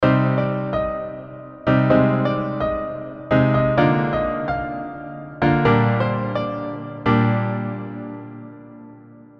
piano.wav